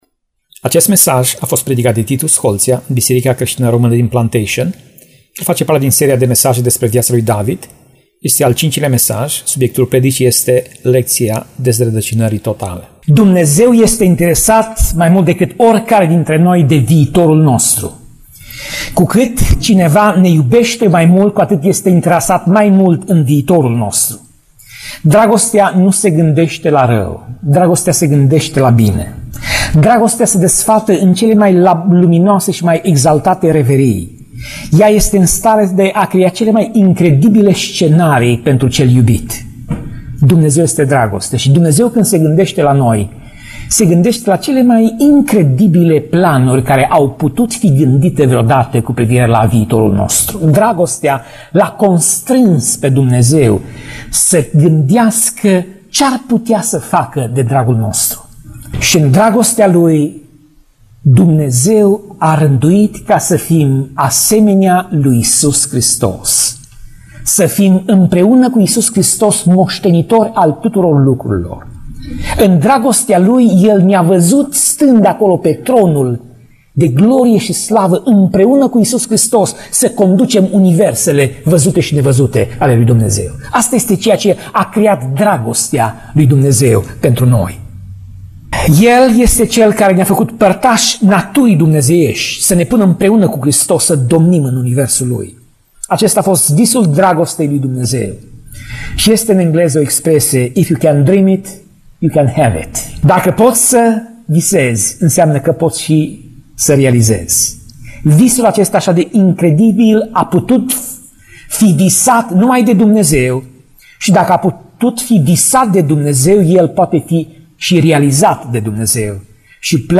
Pasaj Biblie: 1 Samuel 20:30 - 1 Samuel 20:33 Tip Mesaj: Predica